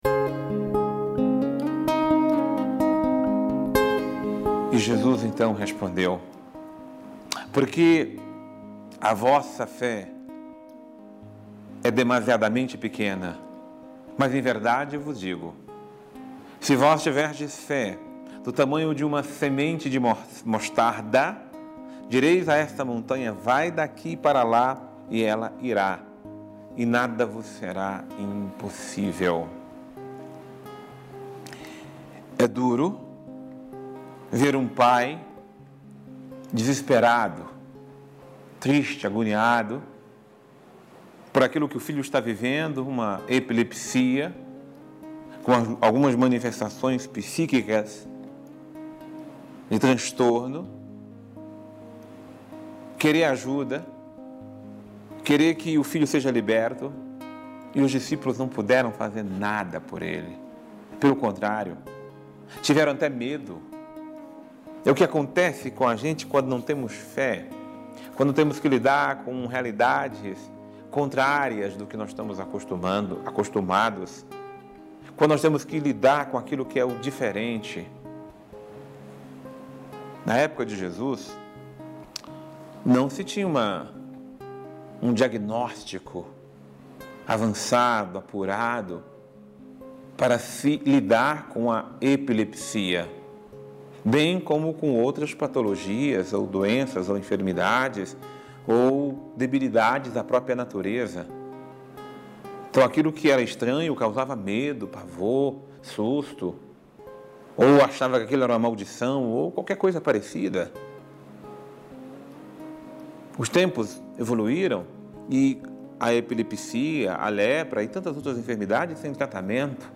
Homilia diária | Depositemos em Deus toda a nossa fé